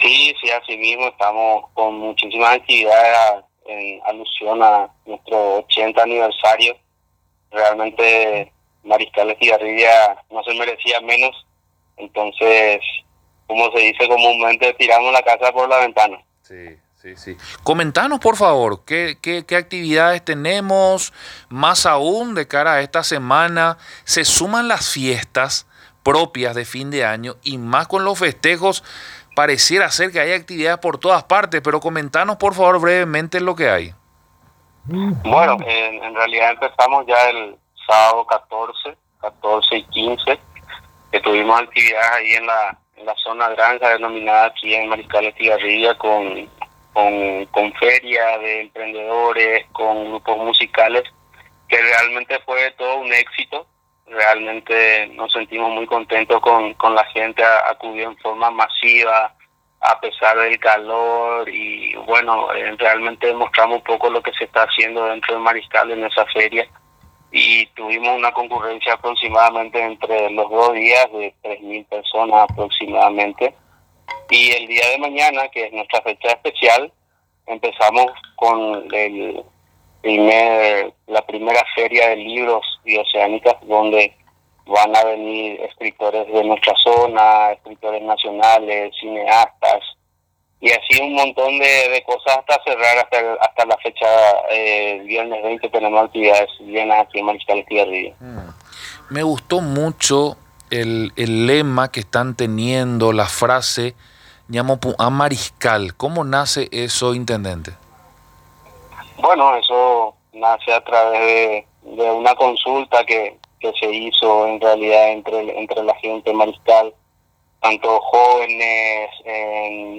INTENDENTE-DE-MARISCAL-ESTIGARRIBIA.m4a